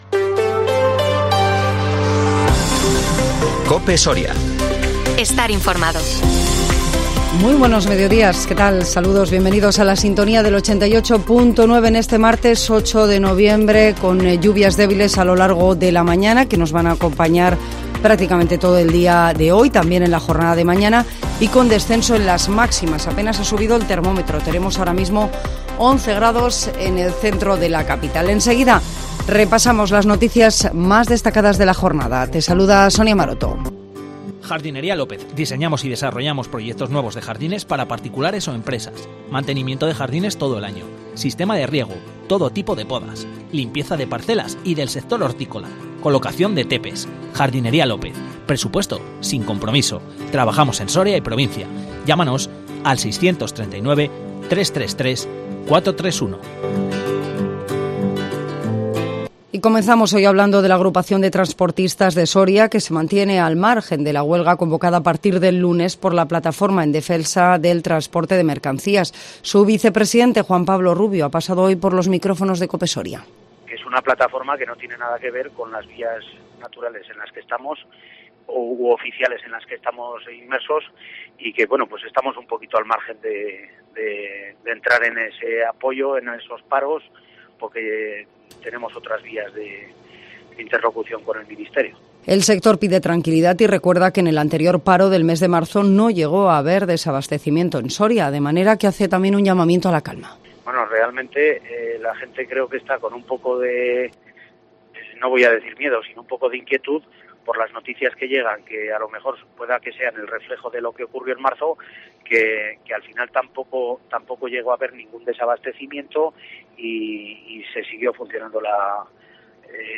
INFORMATIVO MEDIODÍA COPE SORIA 8 NOVIEMBRE 2022